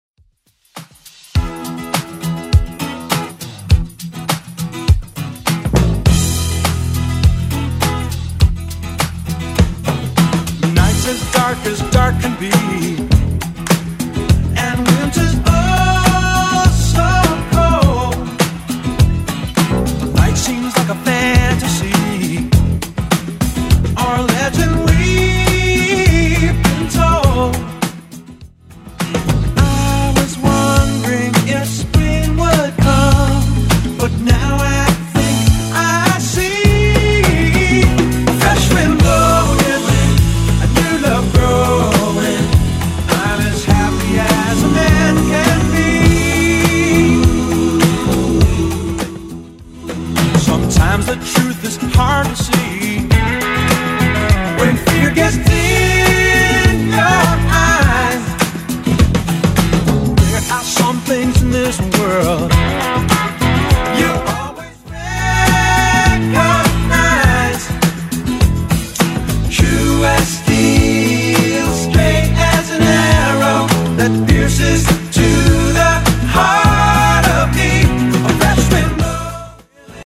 Genre: 80's
BPM: 123